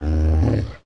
Minecraft Version Minecraft Version latest Latest Release | Latest Snapshot latest / assets / minecraft / sounds / mob / wolf / big / growl3.ogg Compare With Compare With Latest Release | Latest Snapshot
growl3.ogg